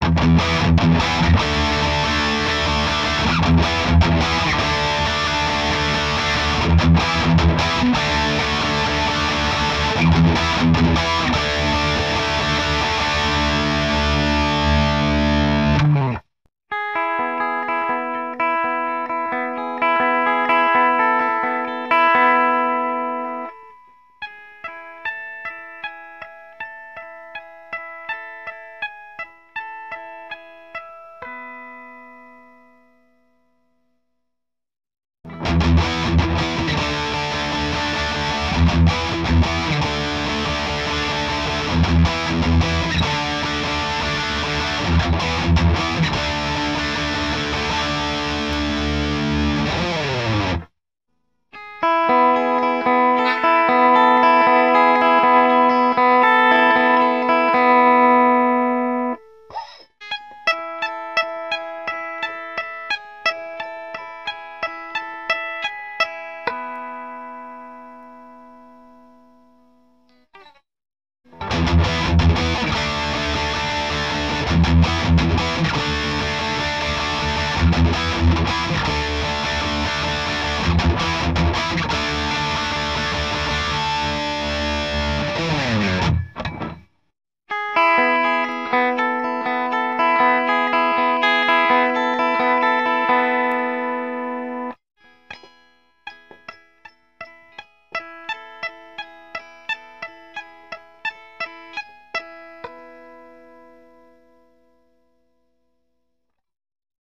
これに同じアンプ設定でAHB-1 BlackoutsとEMG 89を入れてみました。
MTRはMRS-8を使いました。
PEAVEY EXPRESS112
GAIN5.8 LOW6.8 MID6 HIGH7
JB→AHB-1→EMG 89の順番でクリーンも入れてみました。
JBはさすがにパッシブなので音の抜けが良いのとクリーンも奇麗です。
トーンチャート通りなのですがアクティブ差を感じない。
EMG 89は低音にパンチがあります。